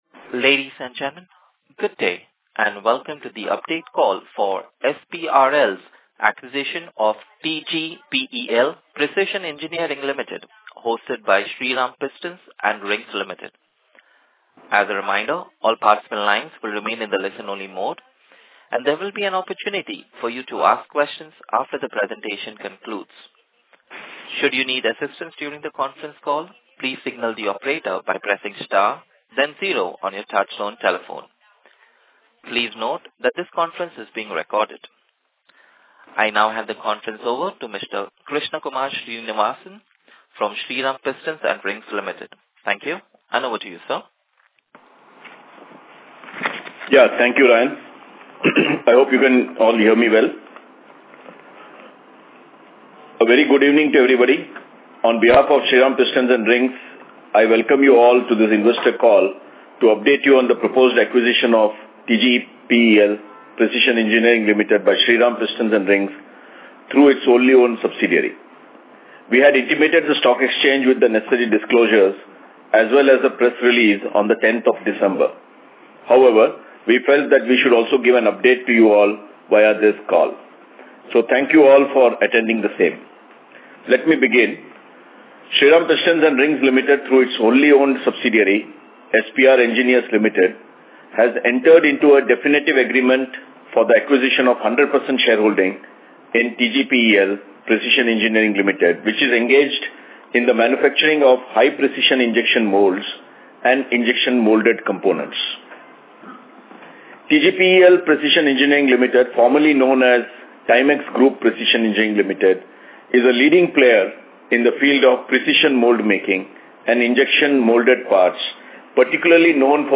Investors Call - Transcripts & Audio Recordings